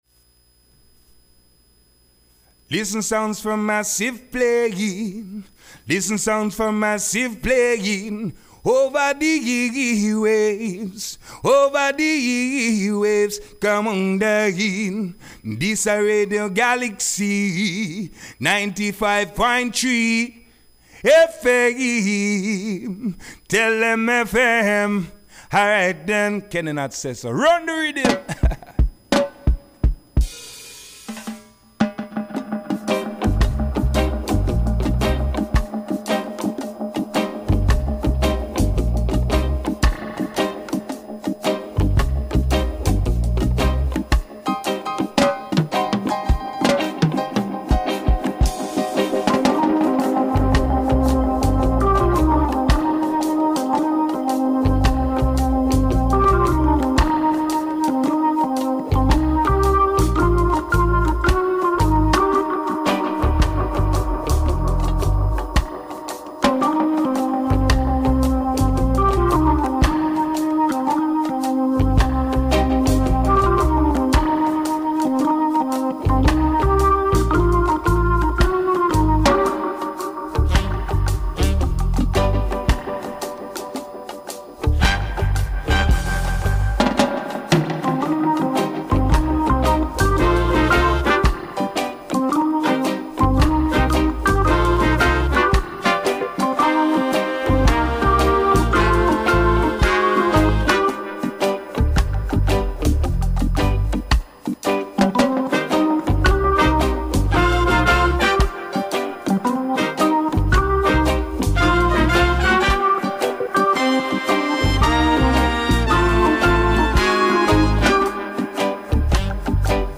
rendez-vous reggaephonique
enregistré hier soir dans les studios